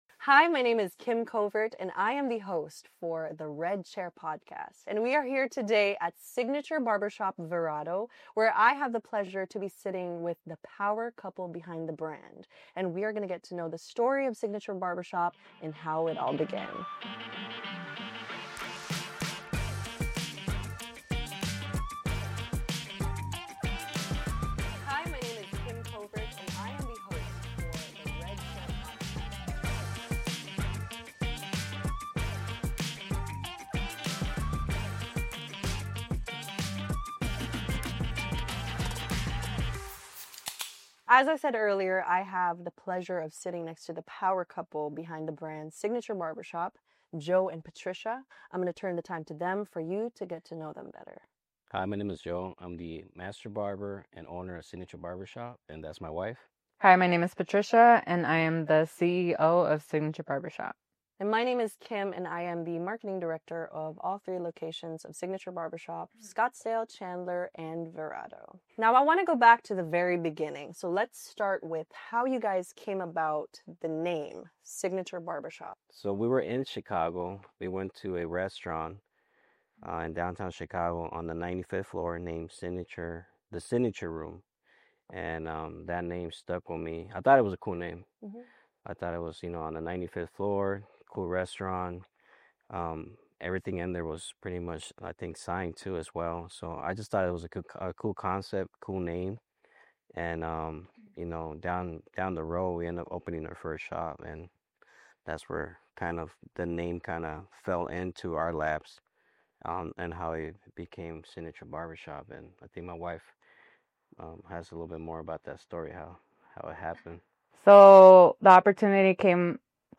From starting with a dream and a pair of clippers to building a thriving barbershop that’s become a pillar of the community, this conversation is packed with grit, wisdom, and inspiration.